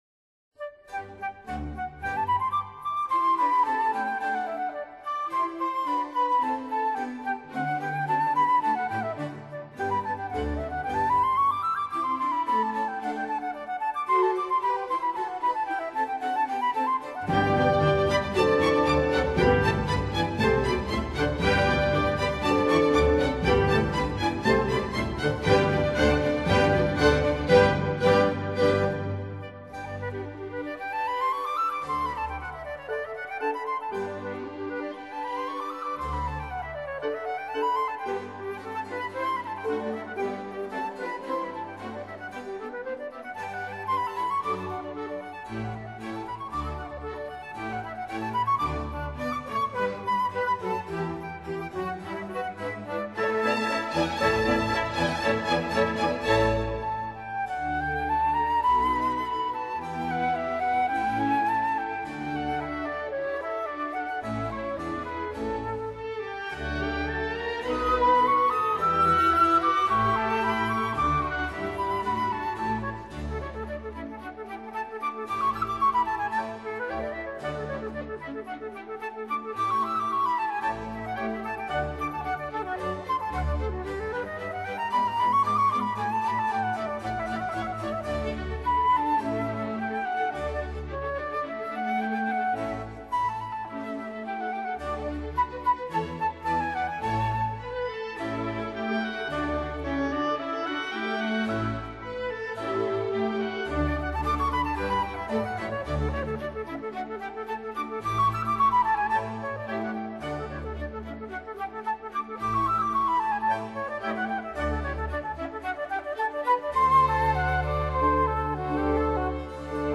Concerto for Flute and Orchestra in G major
Rondo    [0:07:27.15]